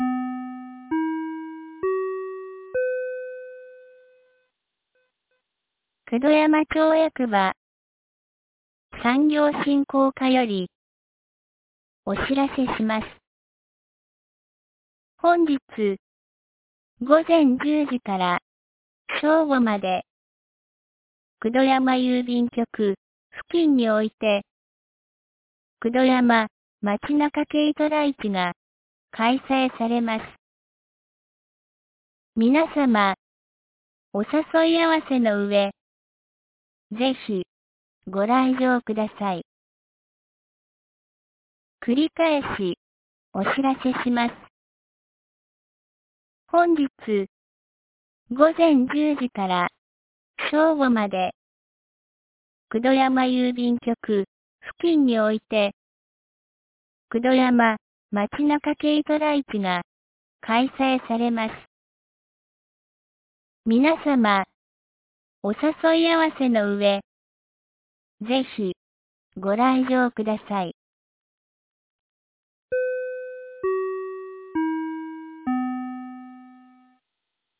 2025年05月18日 09時01分に、九度山町より全地区へ放送がありました。